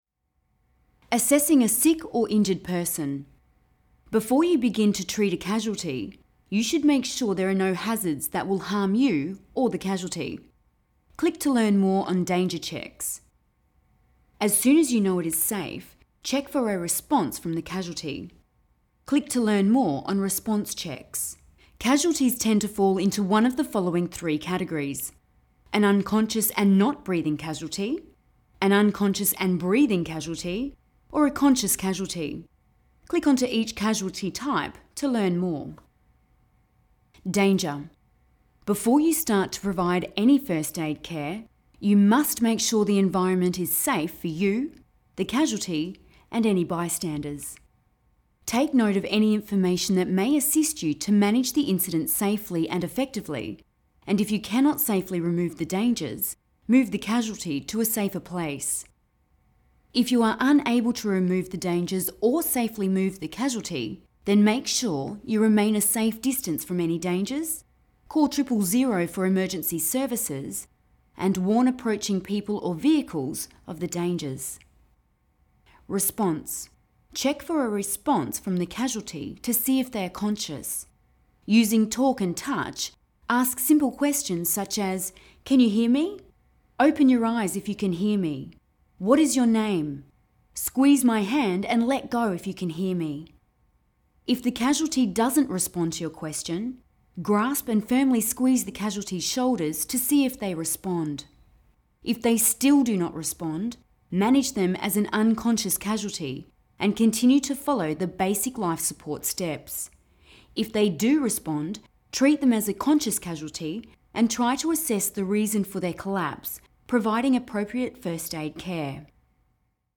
Voiceover
The following is an example of my voiceover work. This was created for 37 South Films to voice an interactive, medical online learning platform.